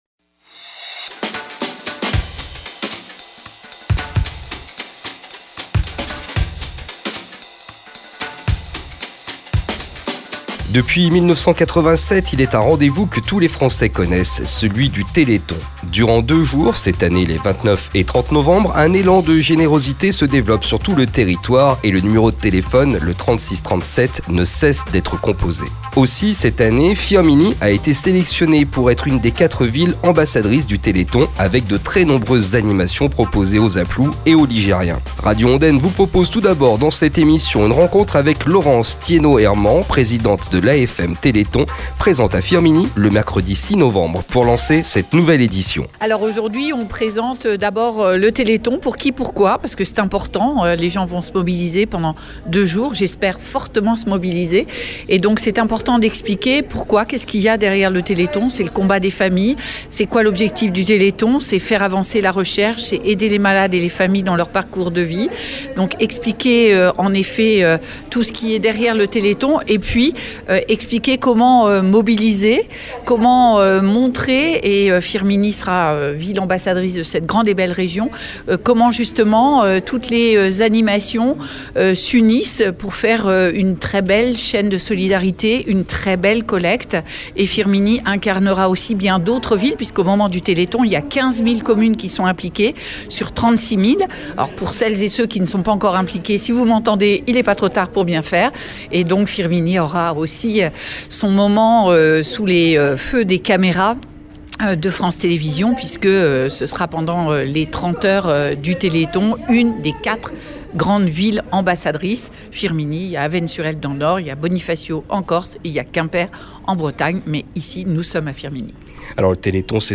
une émission
Maire de Firminy